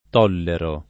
tollerare v.; tollero [